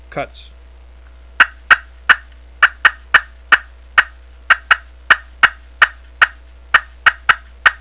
Listen to 8 seconds of cutts
High-Frequency Glass Call